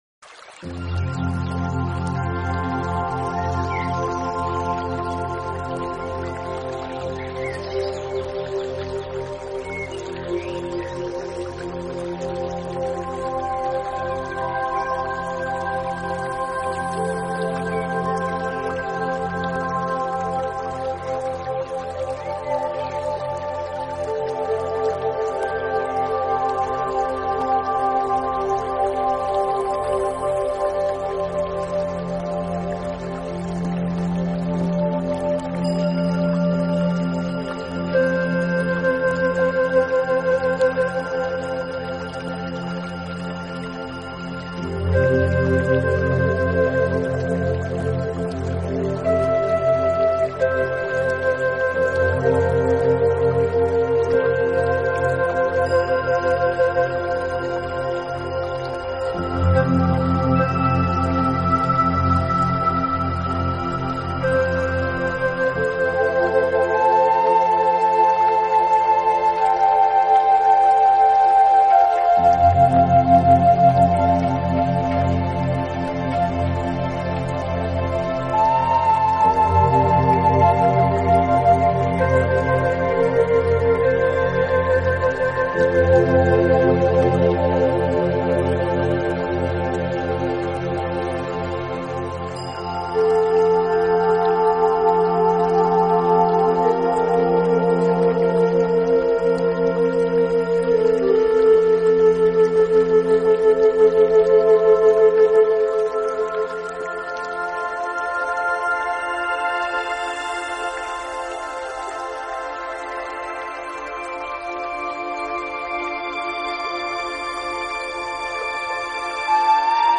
音乐类型：Newage